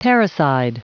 Prononciation du mot : parricide
parricide.wav